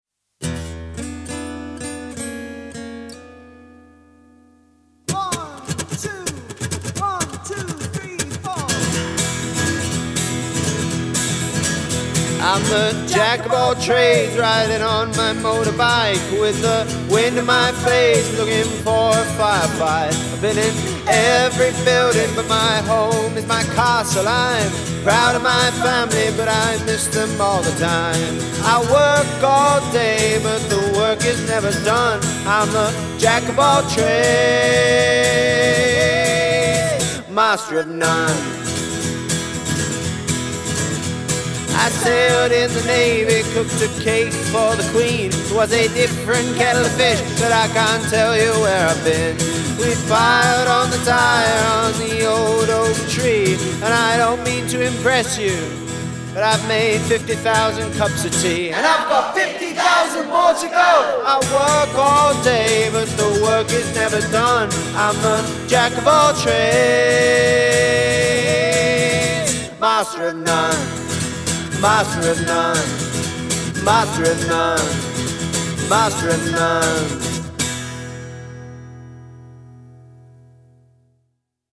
Once we’d written the song together, I took it into a music studio to record it.
All the residents loved that line, and we shouted it all together three times. I added it into the final recording.
Often songs I’ve written with the community are positive, lighthearted songs but this one had a bluesy bite.